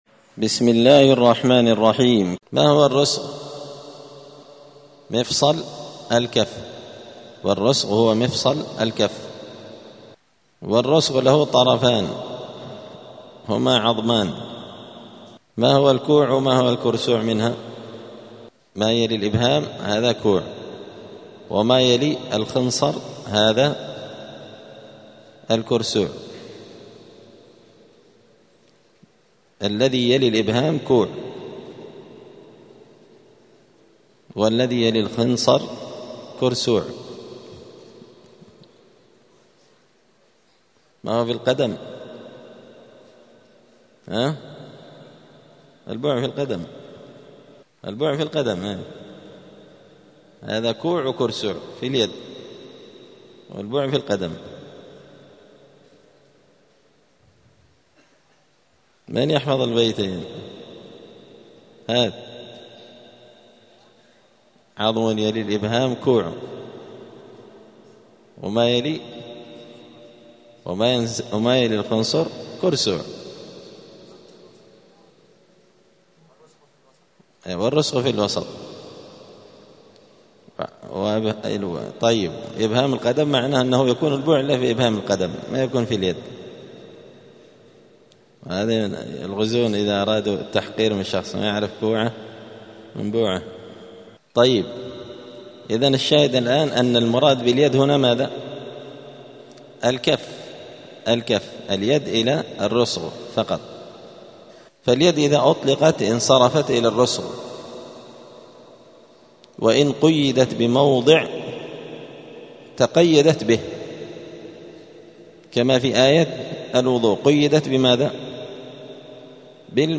دار الحديث السلفية بمسجد الفرقان قشن المهرة اليمن
*الدرس الثاني والتسعون [92] {باب صفة التيمم حكم الاقتصار على اليدين بالكفين في التيمم}*